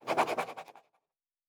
Writing 2.wav